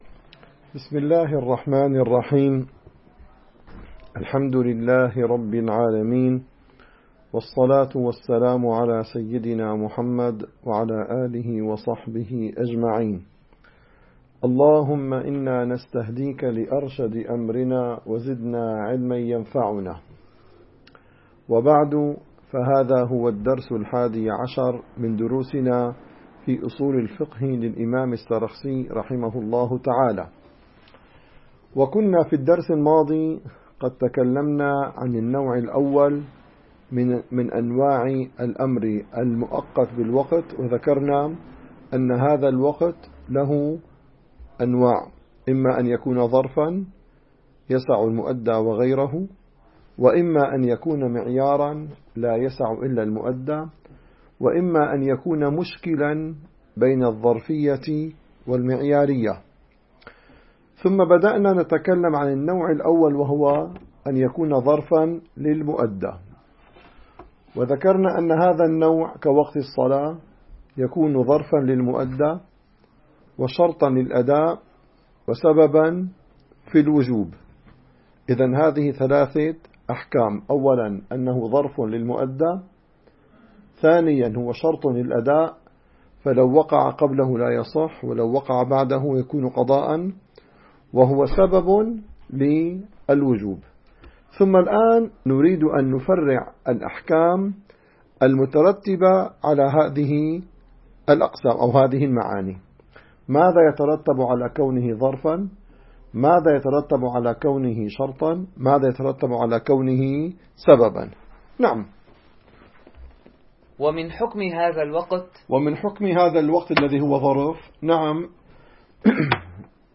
الدرس الحادي عشر: قوله (ومن حكم هذا الوقت أن التعيين لا يثبت بقول)